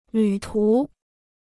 旅途 (lǚ tú) Dictionnaire chinois gratuit